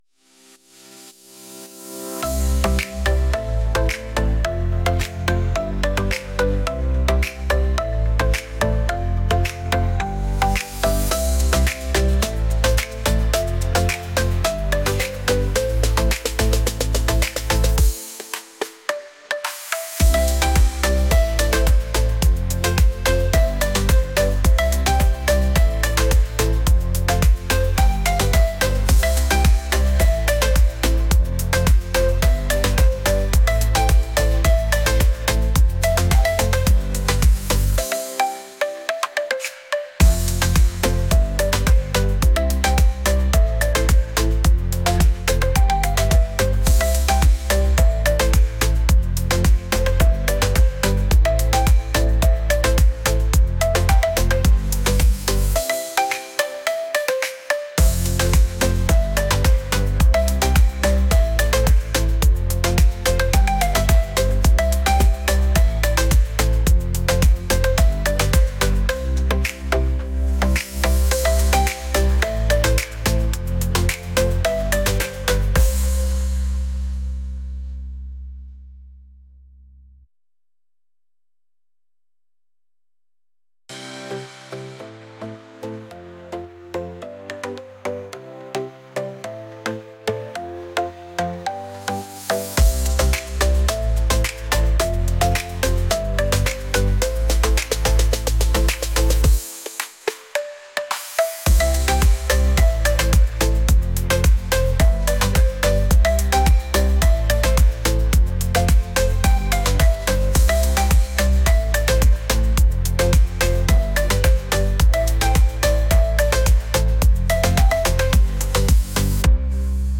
catchy | pop